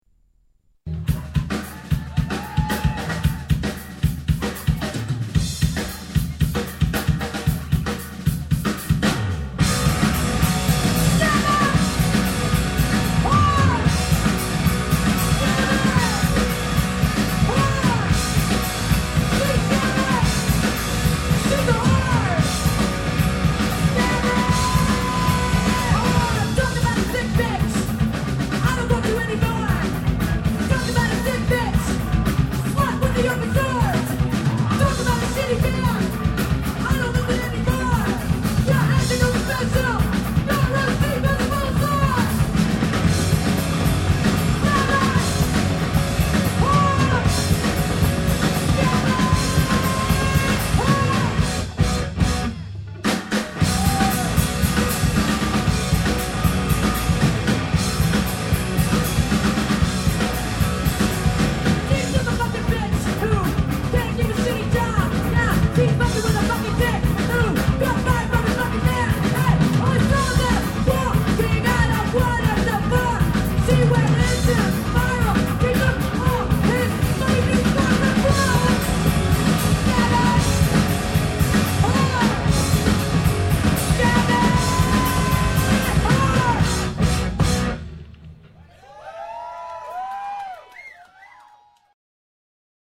Vocals, Guitar
Bass
Lead Guitar
Drums